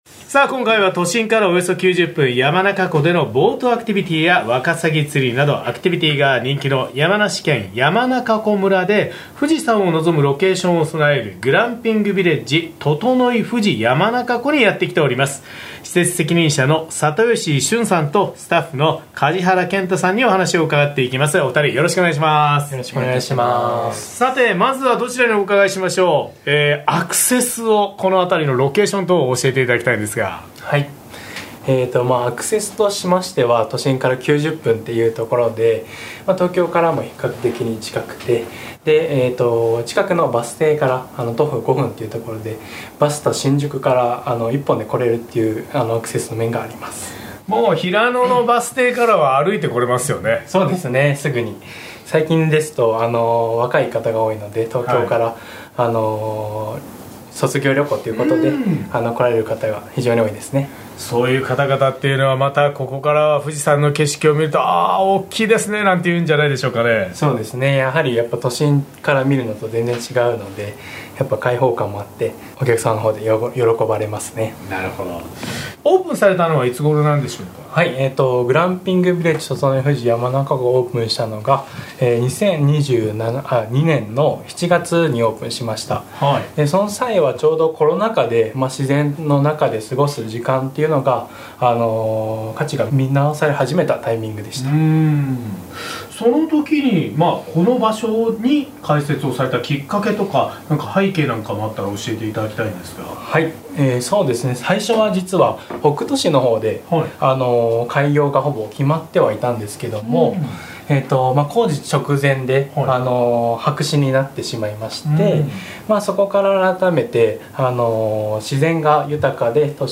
毎週土曜午前11時からの生放送。